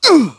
Clause_ice-Vox_Damage_04.wav